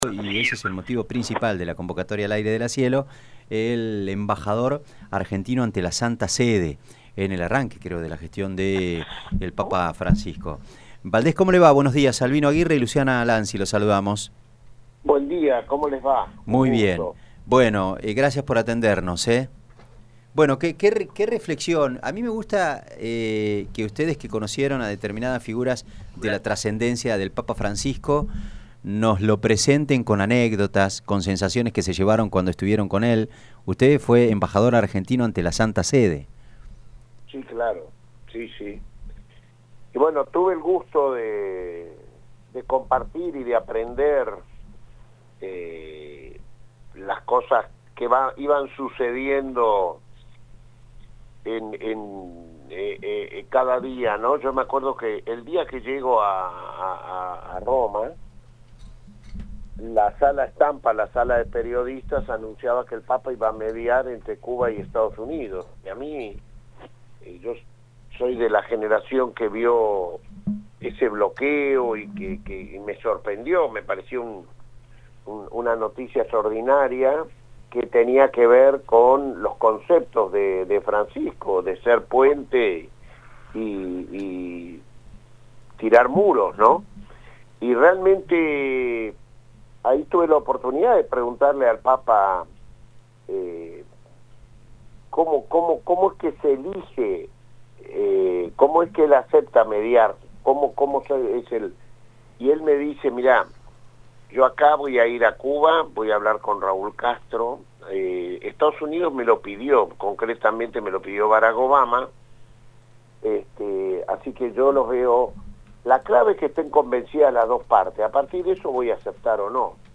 A dos días de la muerte del Sumo Pontífice, seguimos recordando el legado de Francisco a través de las palabras de quienes lo conocieron y lo acompañaron durante su papado. Hoy hablamos con Eduardo Valdés, diputado nacional de Unión por la Patria, quien fue embajador ante la Santa Sede entre 2014 y 2015.